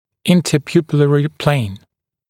[ˌɪntə’pjuːpɪlərɪ pleɪn][ˌинтэ’пйу:пилэри плэйн]межзрачковая плоскость